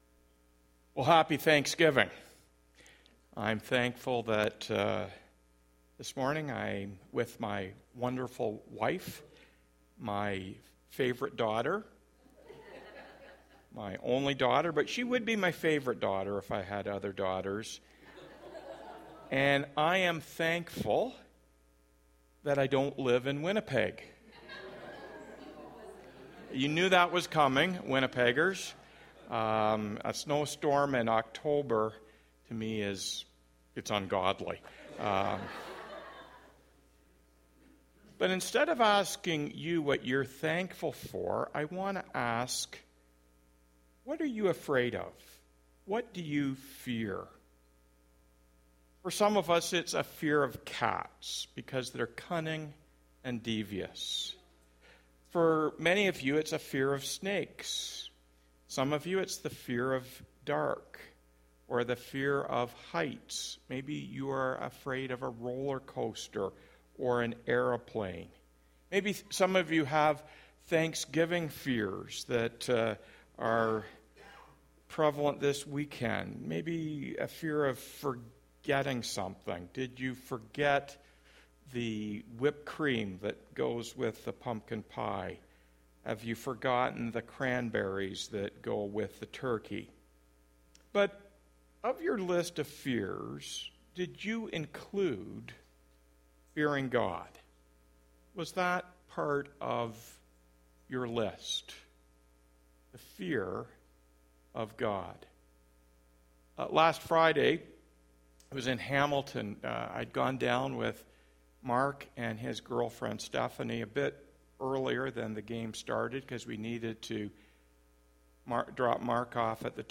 Weekly Sermons - Byron Community Church